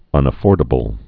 (ŭnə-fôrdə-bəl)